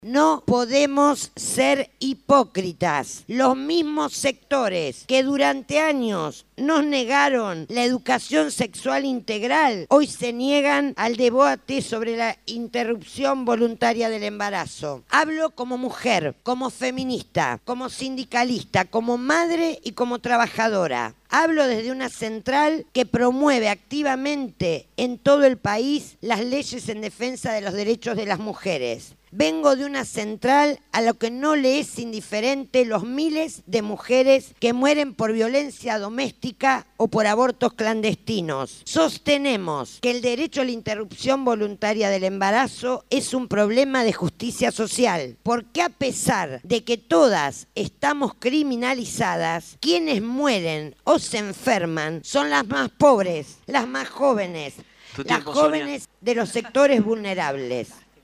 SE DESARROLLA EL ÚLTIMO DÍA DE EXPOSICIONES POR LA LEY DEL ABORTO